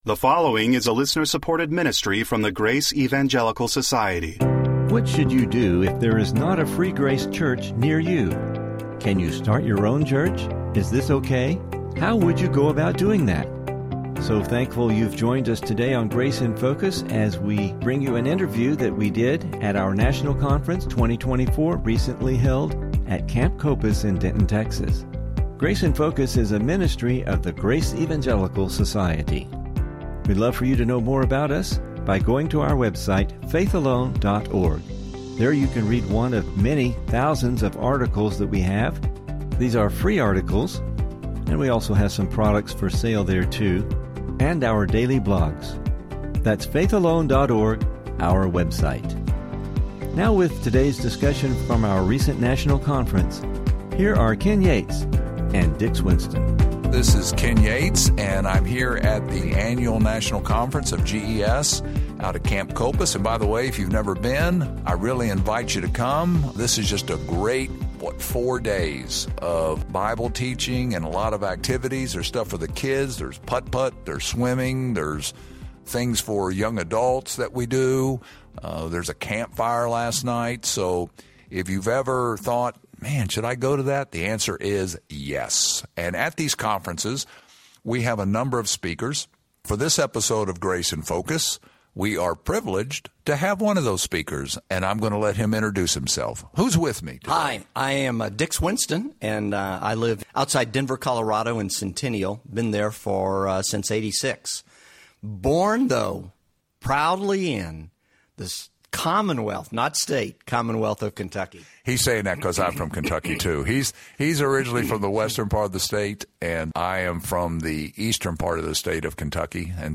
Welcome to Grace in Focus radio.